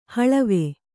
♪ haḷave